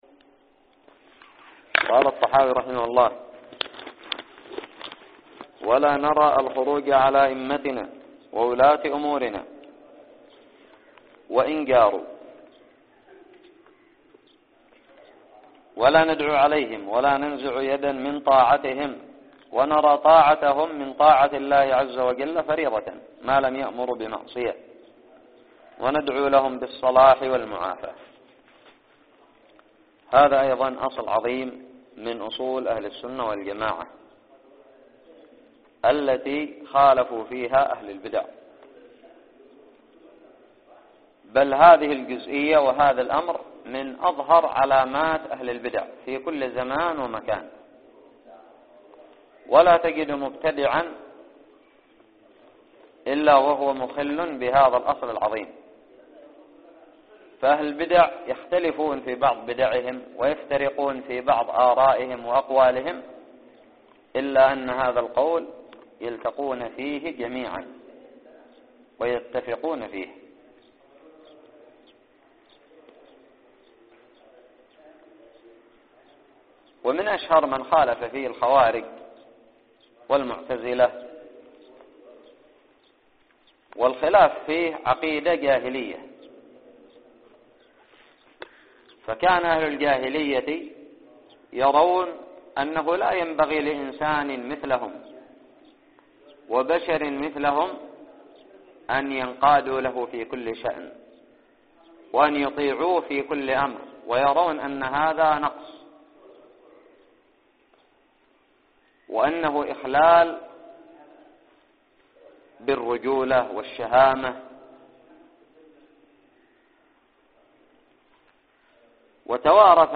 ألقيت في دار الحديث بدماج